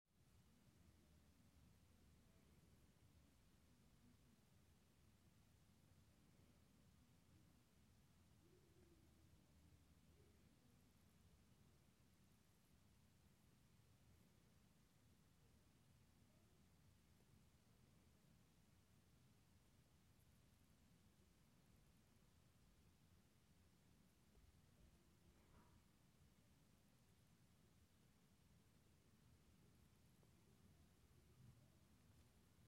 Hi I am experiencing significant low-frequency noise disturbances from the fitness studio below my apartment and need help recording these noises.
Attached the sound track that I recorded by my phone.